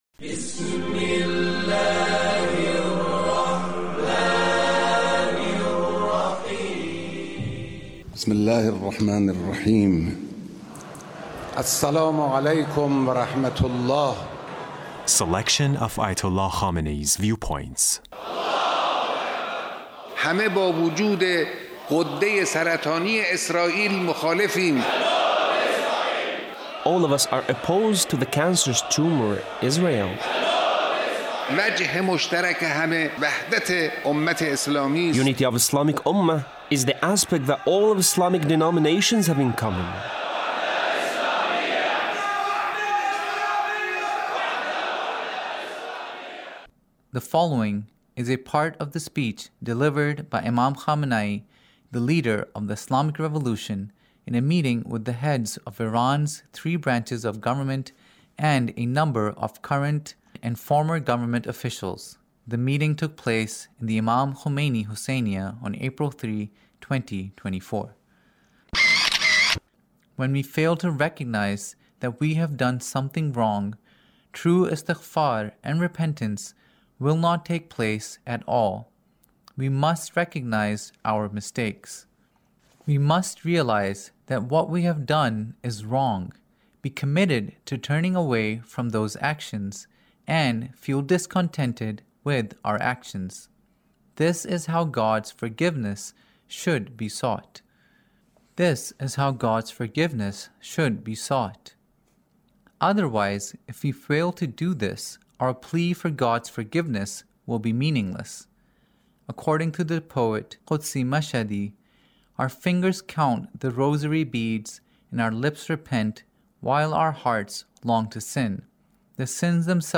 Leader's Speech (1870)